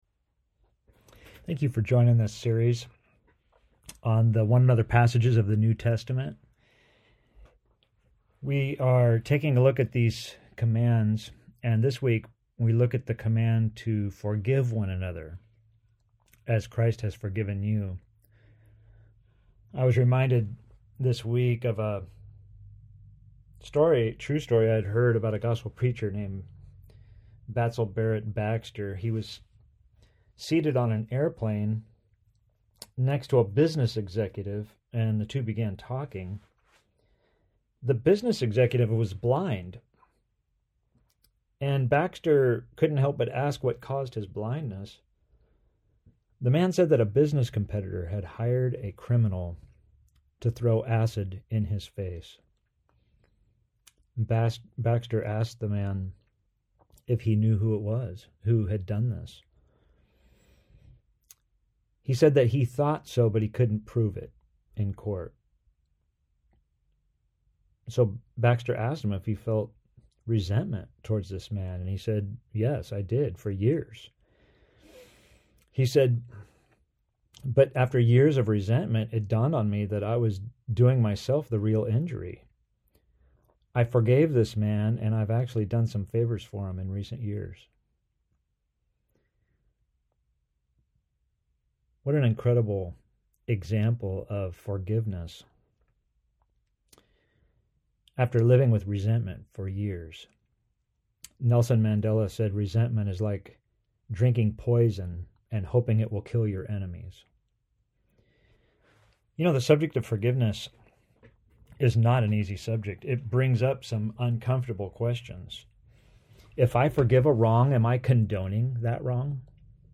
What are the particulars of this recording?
Sermon pre-recorded for Sunday 10/27/20 AUDIO | TEXT PDF